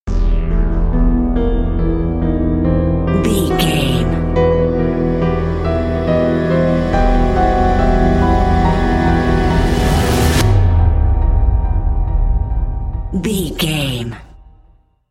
Thriller
Aeolian/Minor
piano
synthesiser
tension
ominous
dark
suspense
mysterious
haunting
creepy